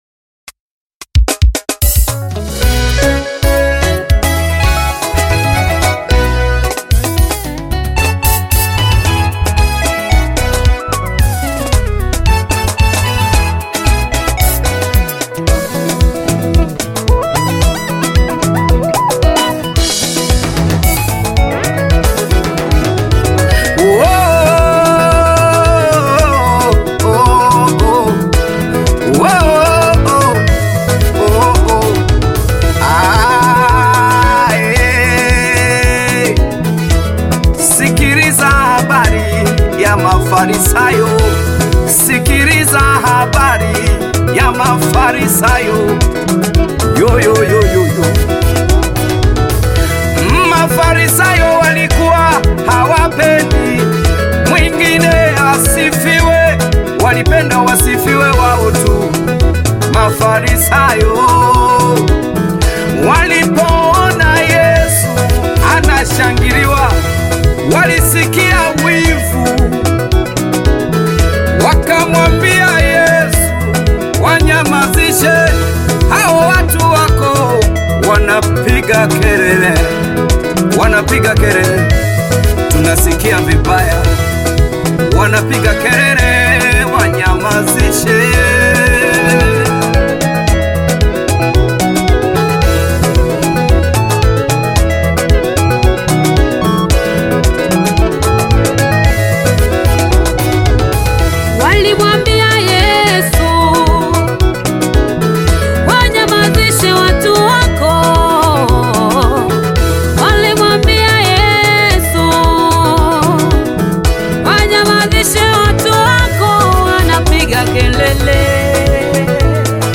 Tanzanian gospel singer
gospel song